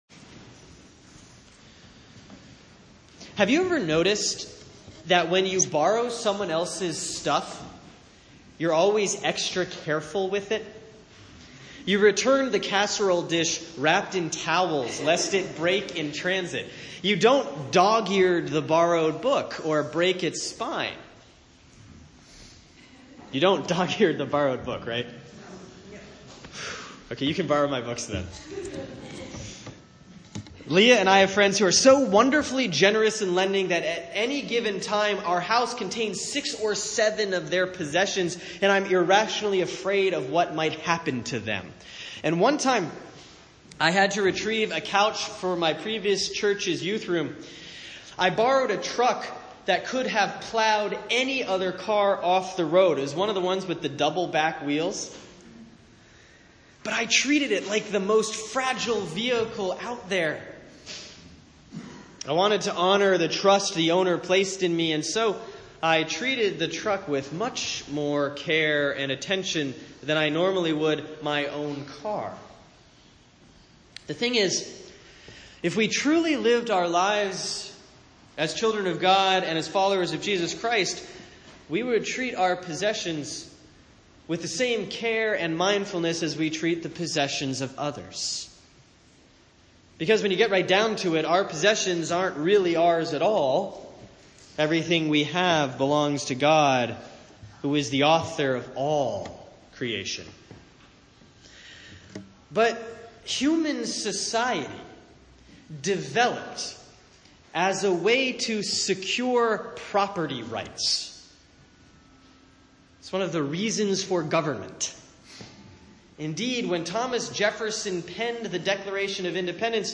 Sermon for Sunday, October 8, 2017